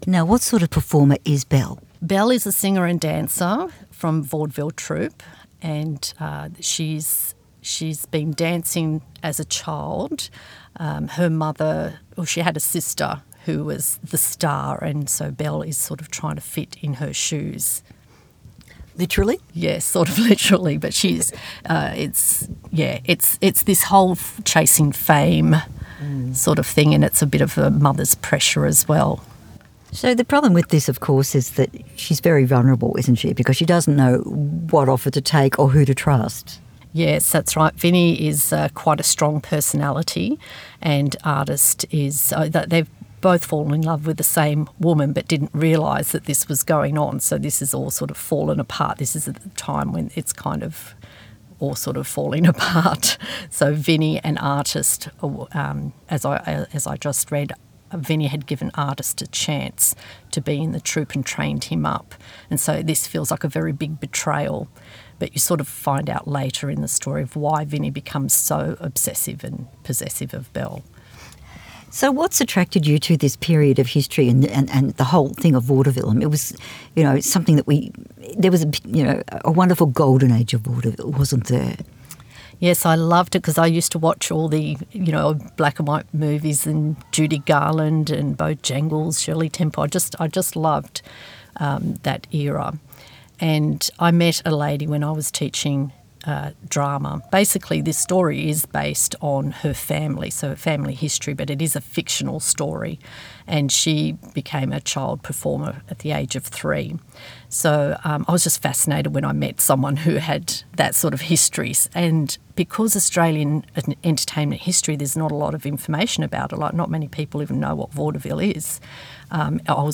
When The Glitter Fades - The Narratives Library Interview